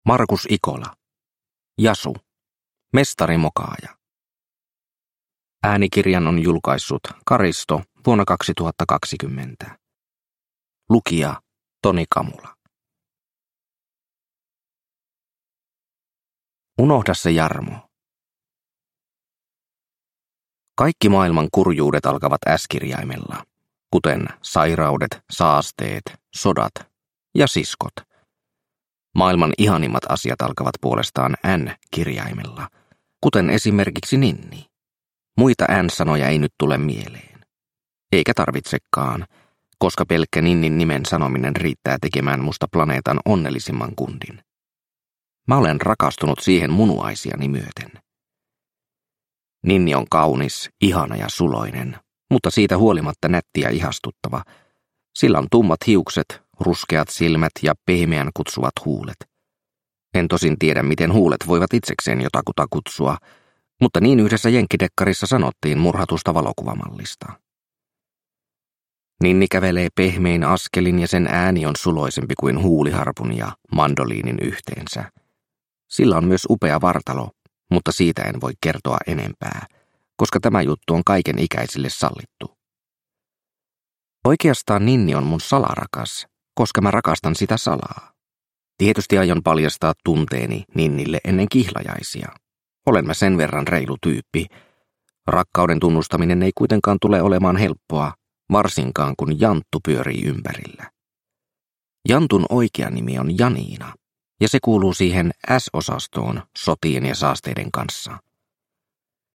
Jasu mestarimokaaja – Ljudbok – Laddas ner